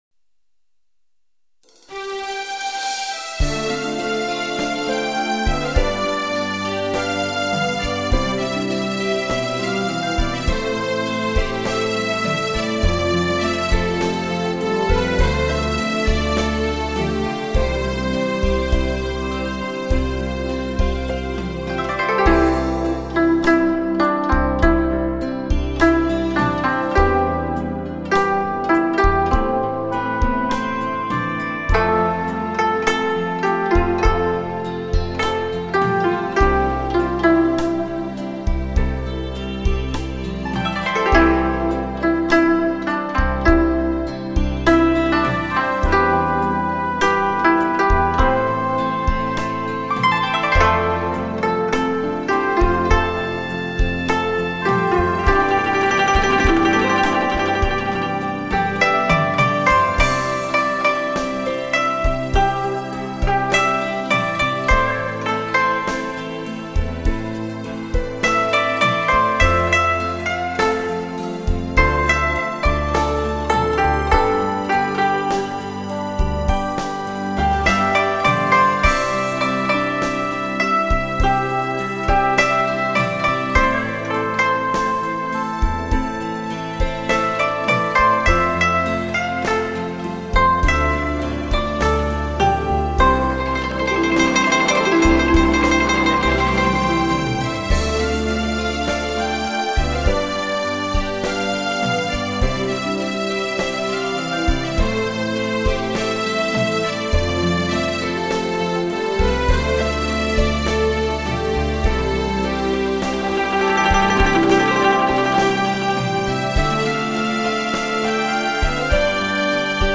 dts-CD试音碟
古筝曲